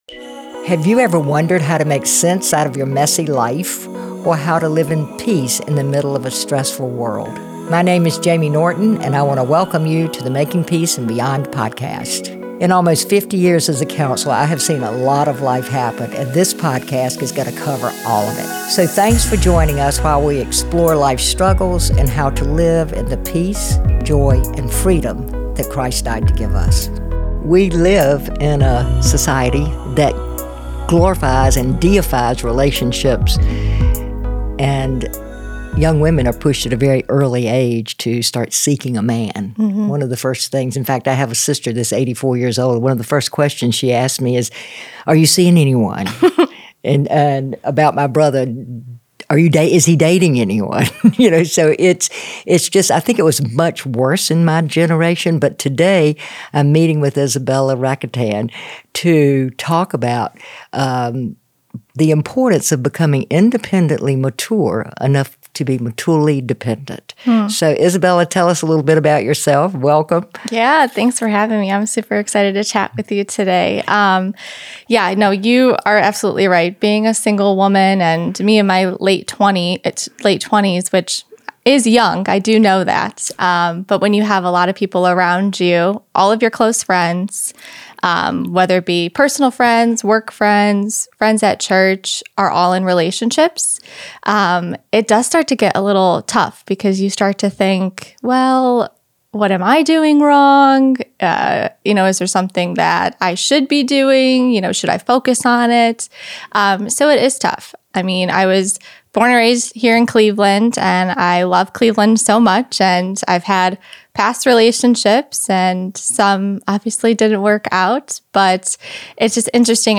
This episode is a refreshing reminder that true joy and purpose come from God, not from other people. If you’re navigating singleness or simply want to embrace your worth in Christ, this conversation is for you.